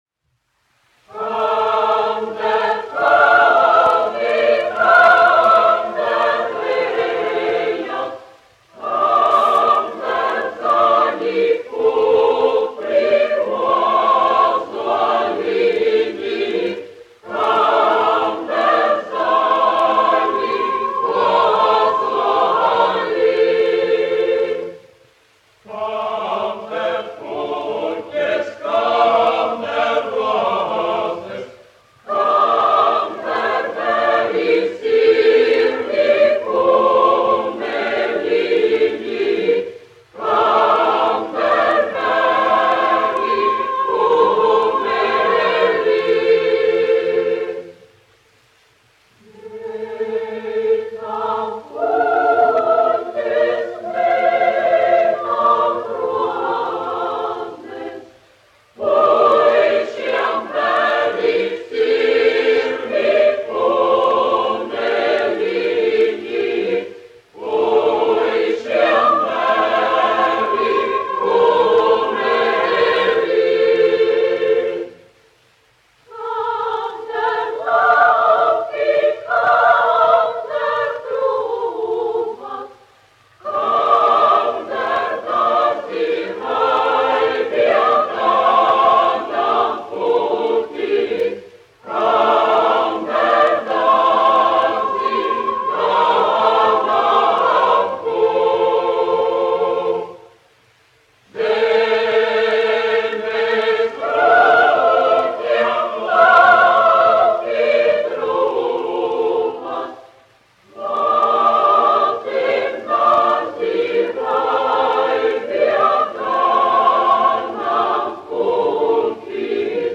Kalni un lejas : latviešu tautas dziesma
Jurjāns, Pāvuls, 1866-1948, aranžētājs
Latvijas Radio koris, izpildītājs
Kalniņš, Teodors, 1890-1962, diriģents
1 skpl. : analogs, 78 apgr/min, mono ; 25 cm
Latviešu tautasdziesmas
Kori (jauktie)